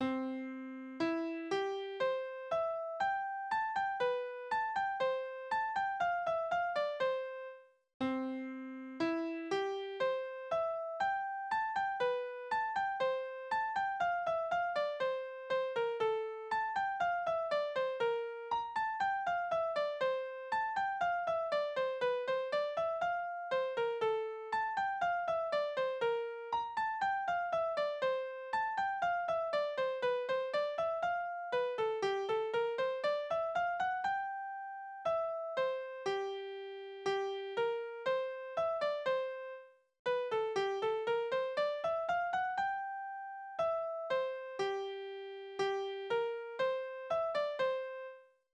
Kastilianer Tanzverse Tonart: C-Dur, F-Dur Taktart: 2/4 Tonumfang: große Septime über zwei Oktaven
Instrumentalstück aufgrund des großen Ambitus' und der großen Intervallsprünge